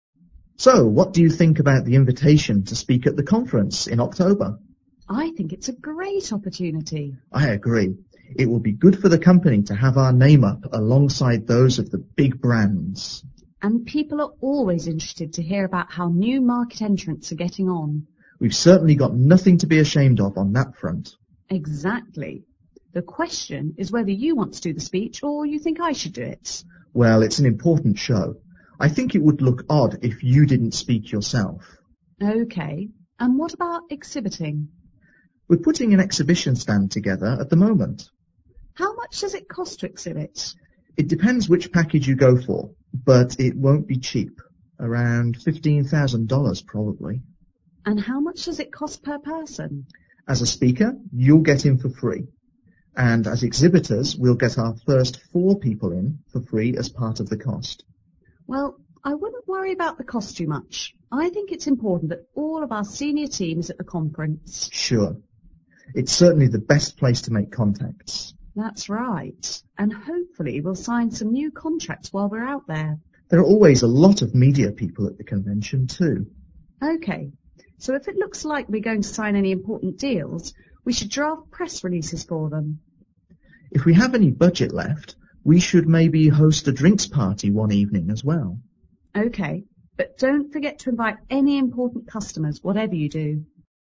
Listen to the dialogue between the Company President and Head of Marketing,